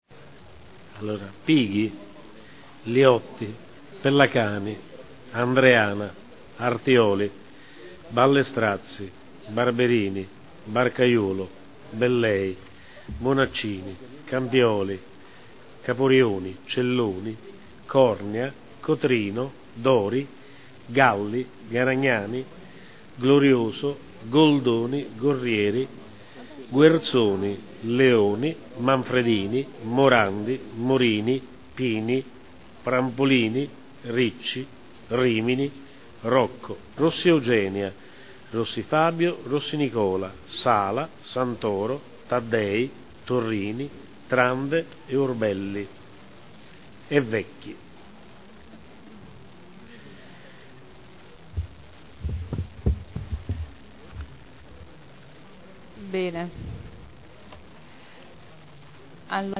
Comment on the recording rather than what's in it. Seduta del 01/02/2010. Appello